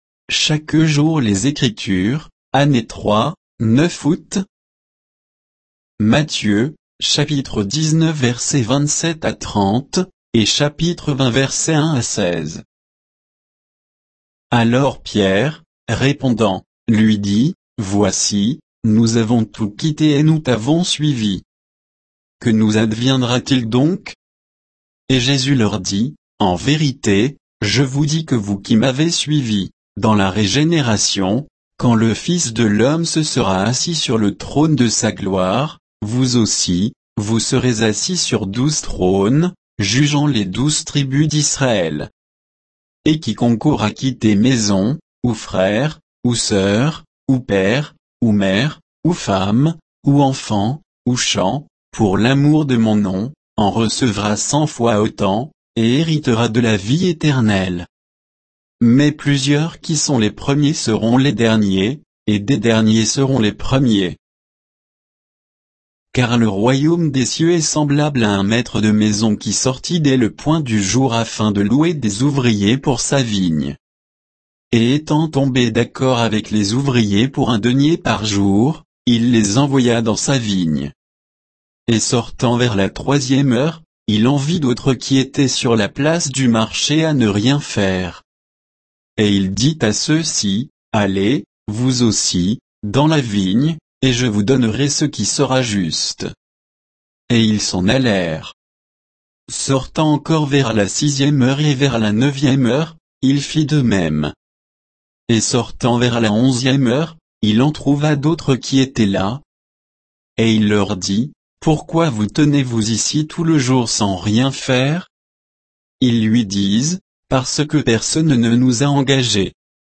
Méditation quoditienne de Chaque jour les Écritures sur Matthieu 19, 27 à 20, 16